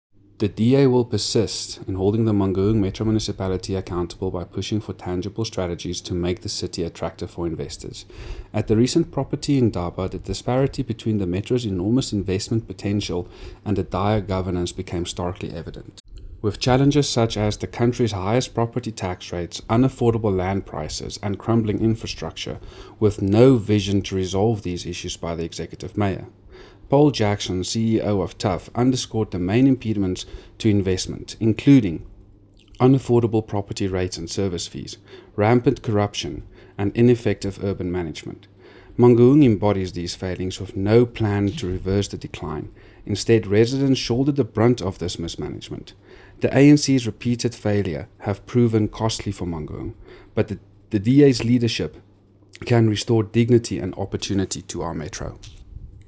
Afrikaans soundbites by Cllr Andre Snyman and